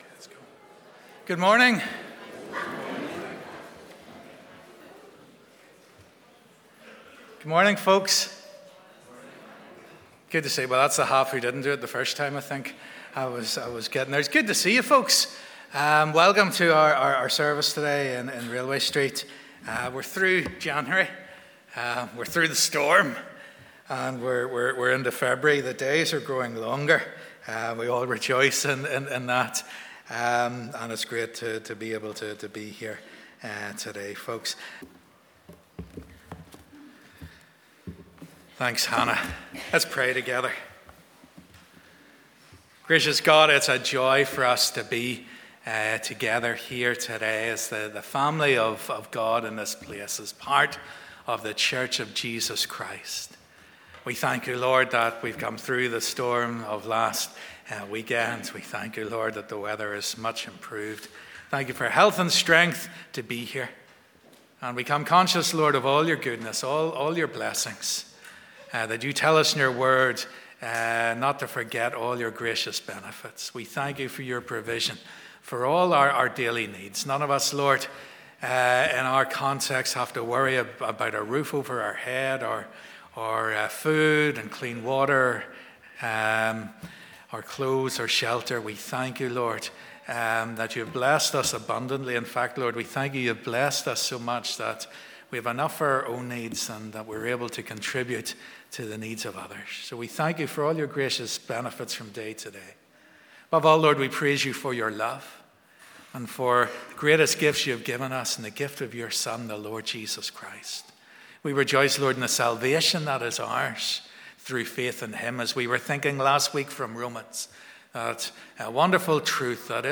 This morning all ages in our church family are staying together throughout the service as we look at Jesus' Parable of the Sheep and the Goats in Matthew 25.